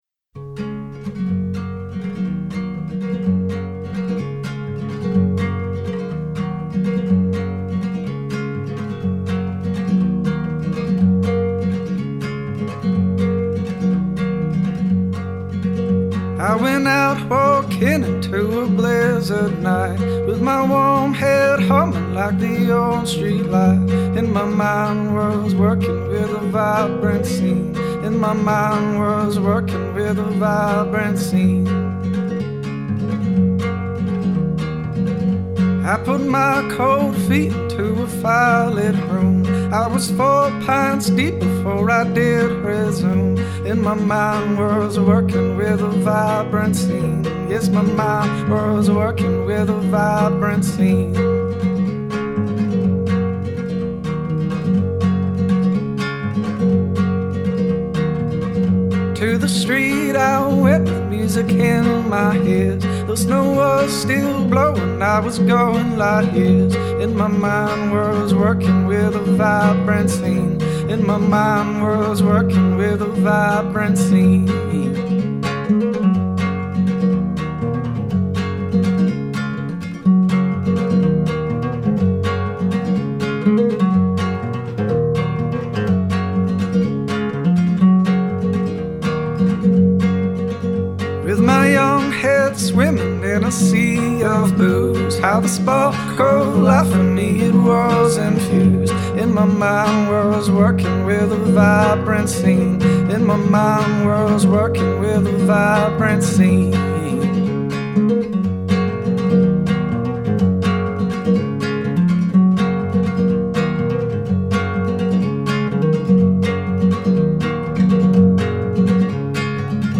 Canadian Chill Folk
West Coast Chill Roots
singer-guitarist
sent me his new solo record.
pretty folky